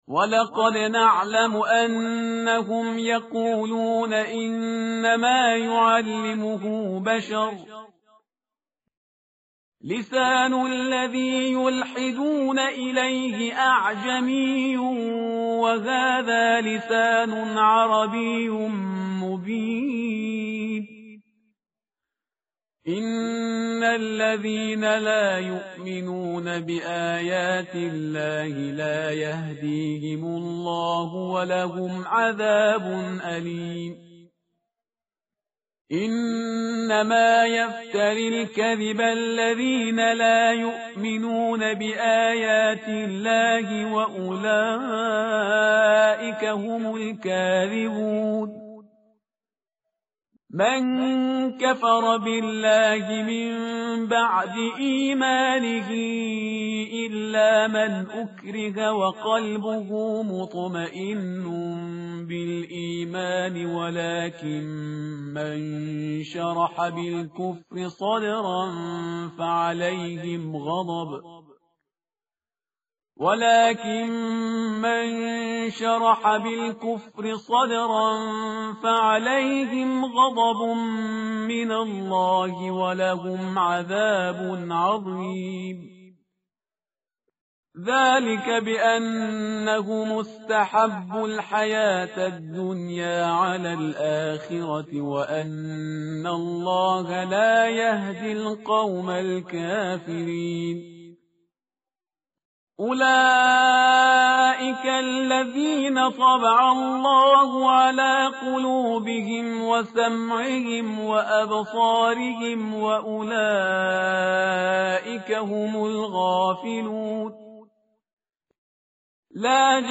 tartil_parhizgar_page_279.mp3